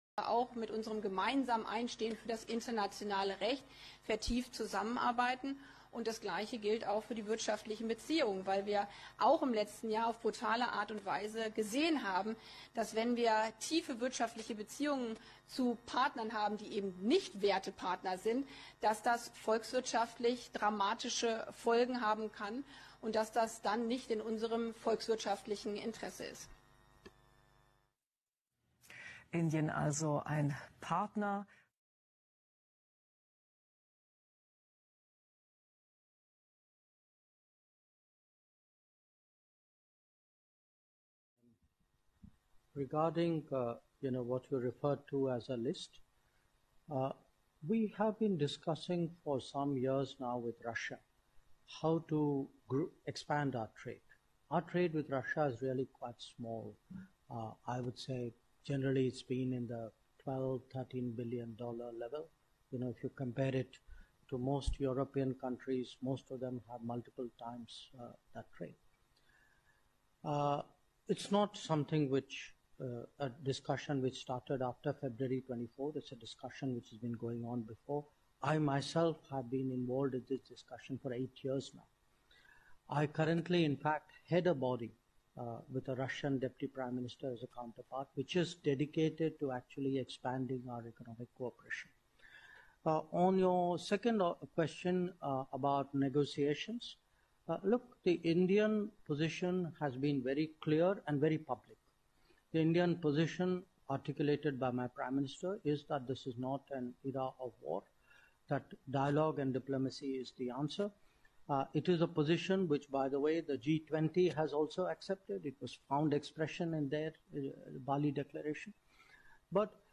Komplette Pressekonferenz der Aussenminister Indien und Deutschland
Auszug der Pressekonferenz der Aussenministerin Baerbock mit dem indischen Aussenminister.